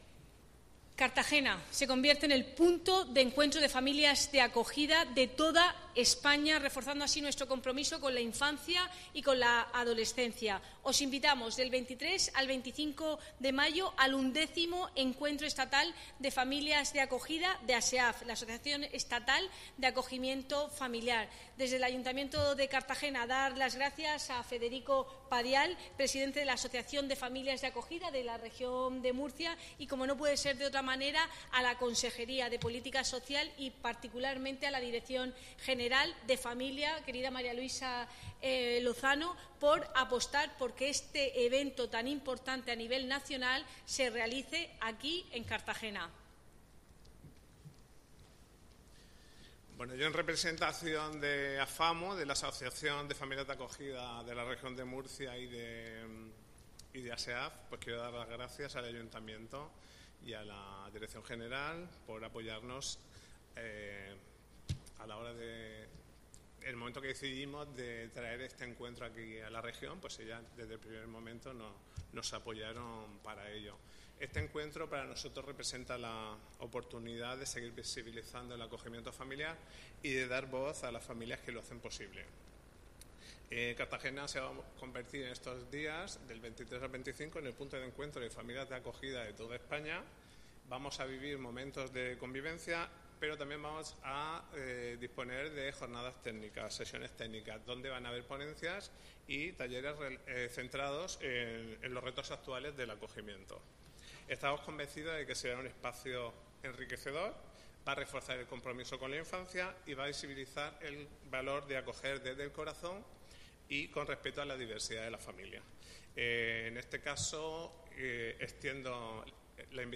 Este encuentro ha sido presentado por la edil de Política Social, Cristina Mora; la directora general autonómica de Familias, Infancia y Conciliación, María Luisa Lozano; y el presidente de Afamu